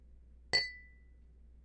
Clanking glass
Bottle Ding field-recording Glass Ring Ting sound effect free sound royalty free Memes